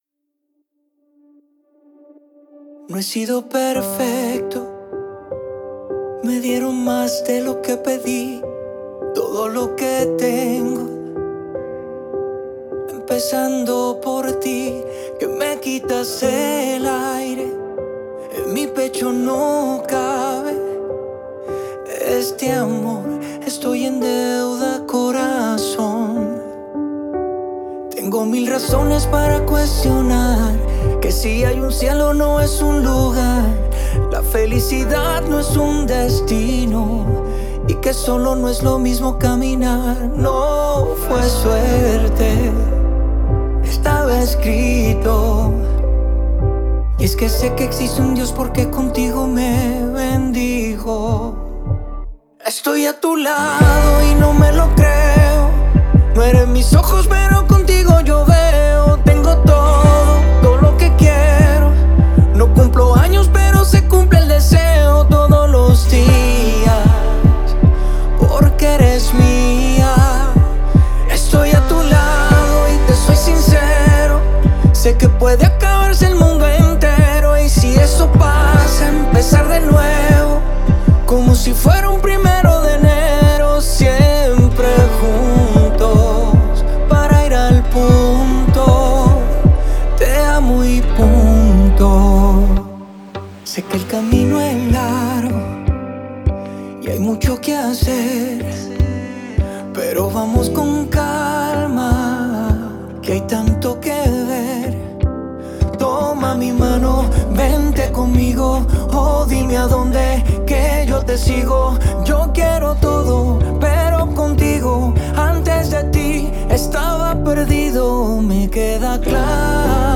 pop latino